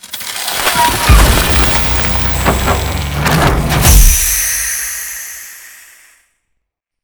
stuck.wav